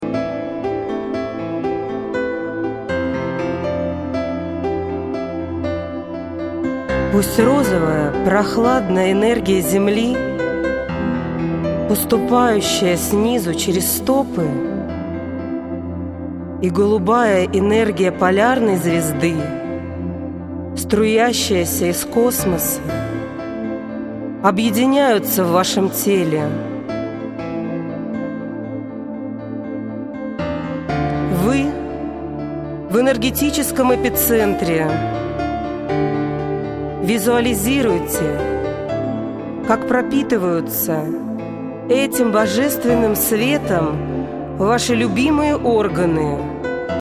Эти медитации были созданы лично мной для ВАС и воспроизведены на студии звукозаписи.
Все медитации я зачитываю СВОИМ ГОЛОСОМ!